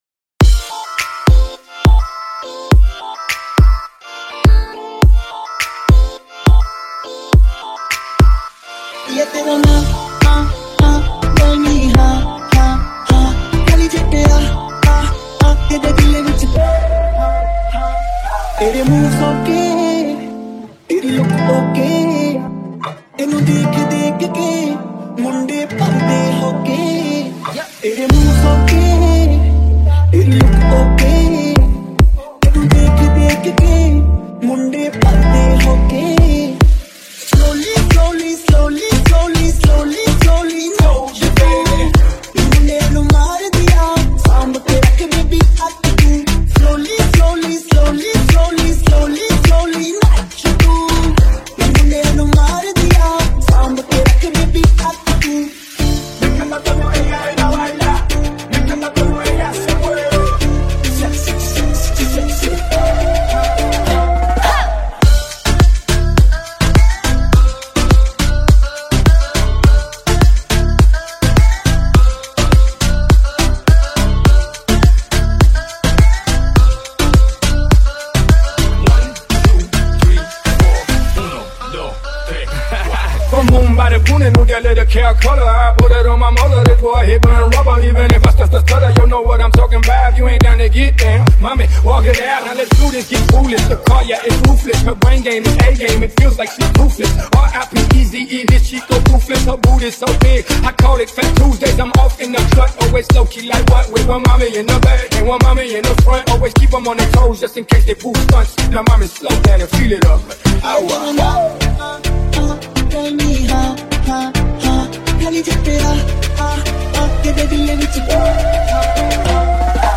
New Dj Song 2023